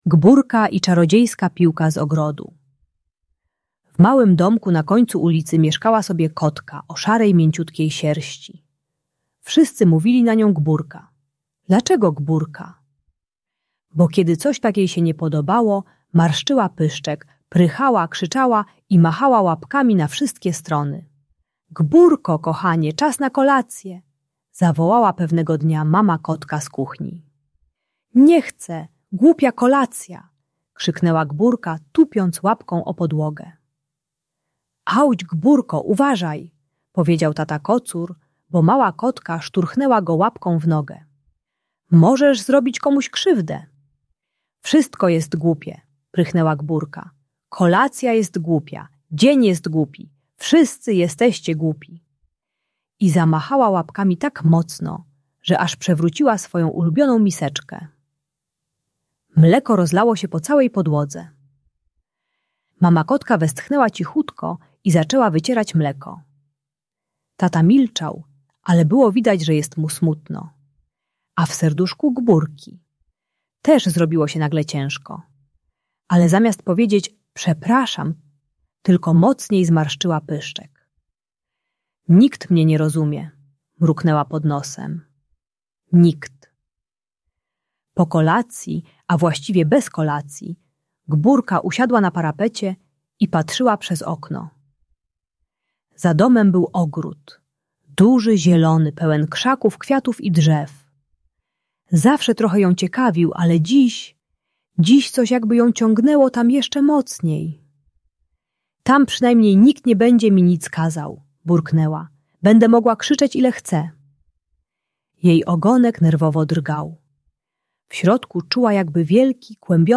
Audiobajka o agresji i złości uczy techniki "STOP, MAŁA ŁAPKO" - jak zatrzymać łapki przed uderzeniem i zamienić brzydkie słowa na wyrażanie uczuć.